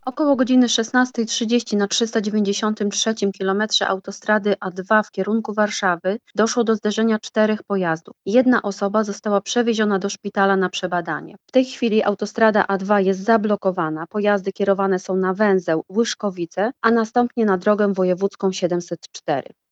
Serwis-wypadek-A2.mp3